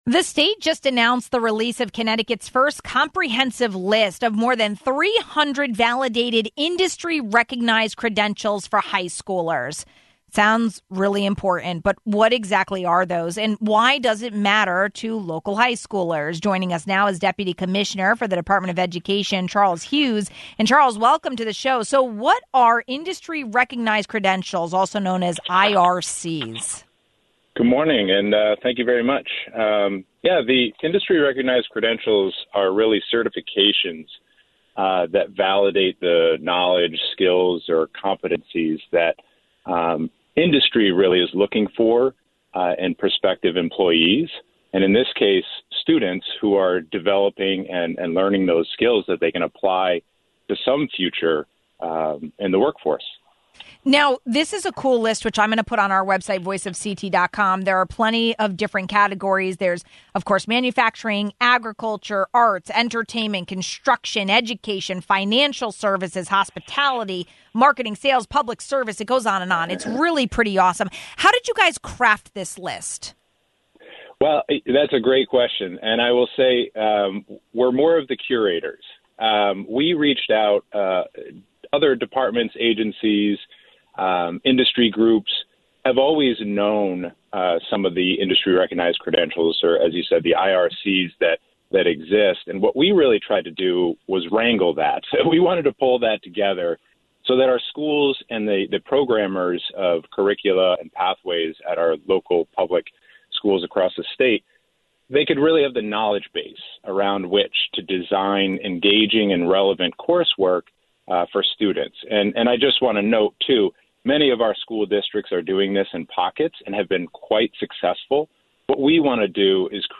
We asked Deputy Commissioner Charles Hewes, from the Connecticut Department of Education.